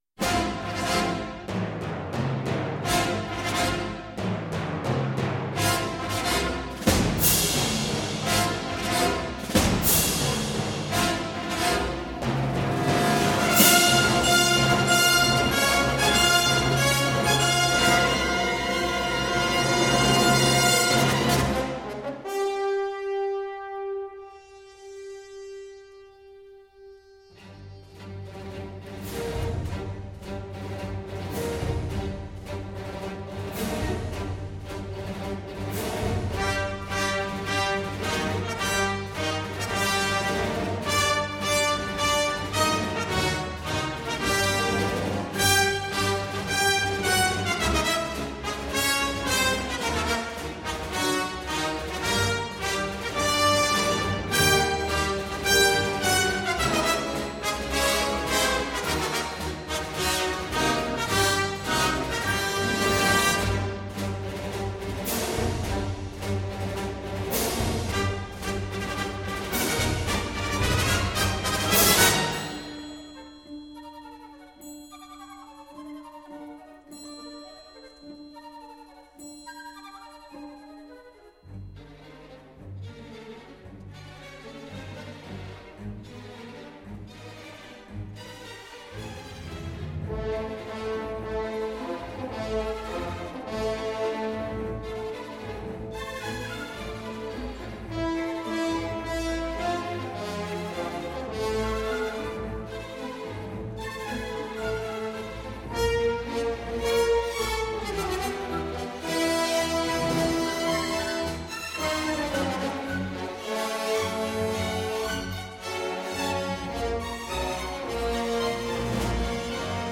et enregistré en numérique « soundstream »